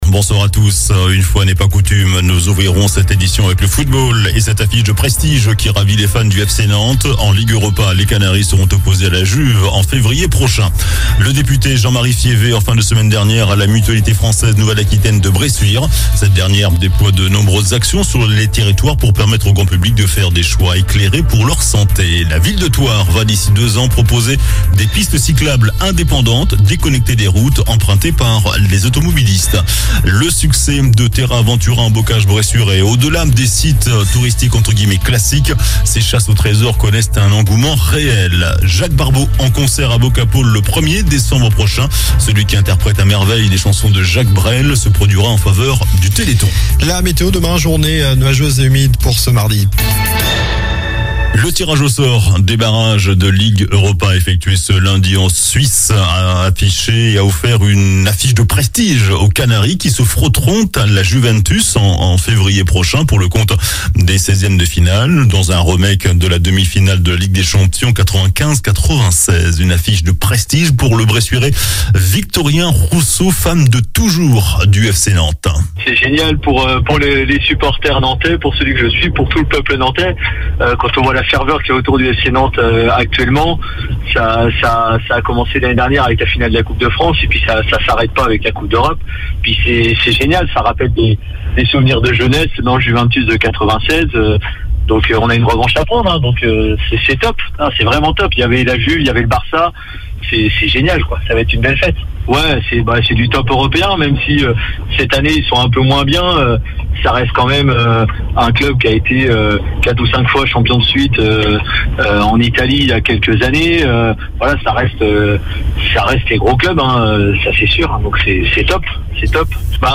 JOURNAL DU LUNDI 07 NOVEMBRE ( SOIR )